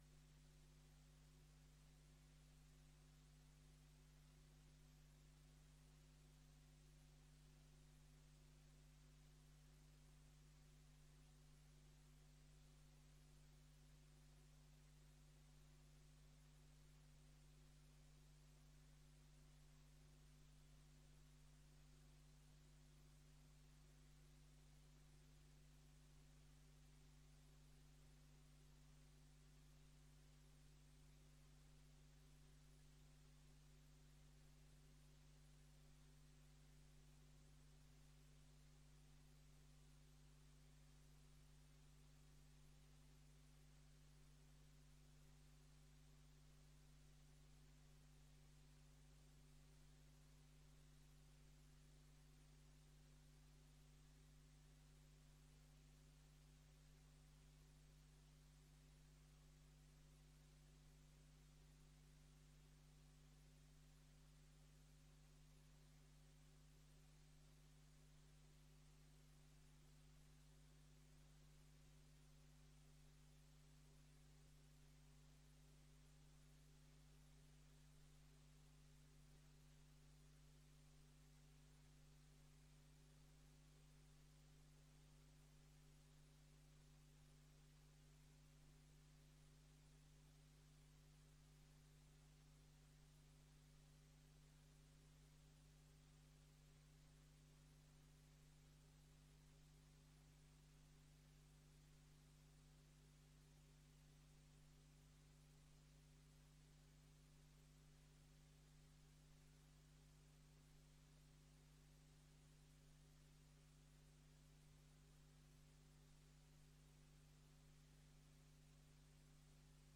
Gemeenteraad 07 juni 2022 21:00:00, Gemeente Dinkelland
Locatie: Raadzaal